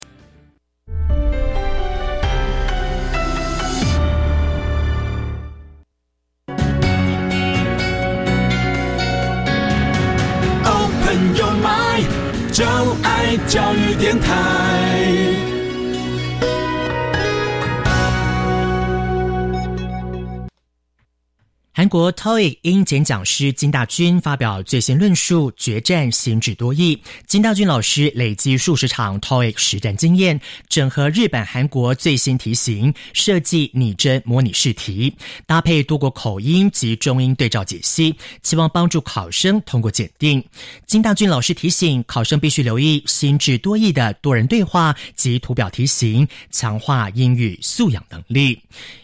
4. MP3 多國口音MP3
完全比照新制多益考試規則，精心錄製多國口音，讓你應考更熟悉！